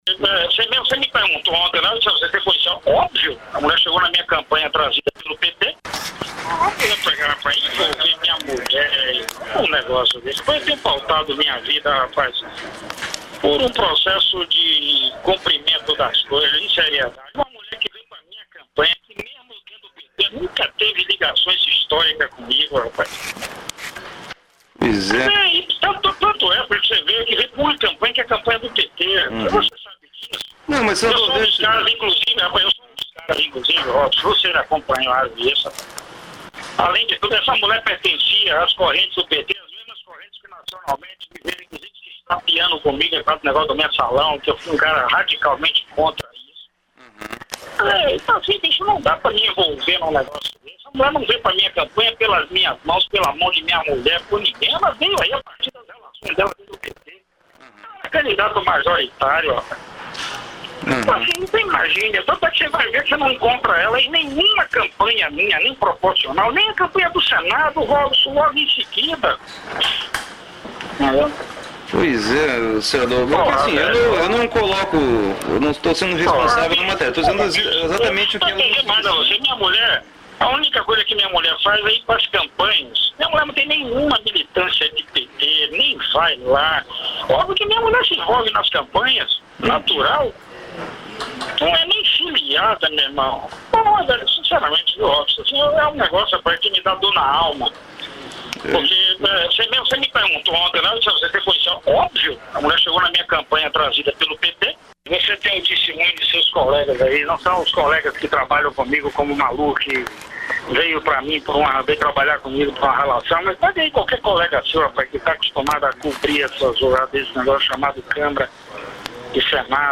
A revista Veja conseguiu conversar com o senador, e divulgou os áudios na sua página oficial nesta terça-feira (23).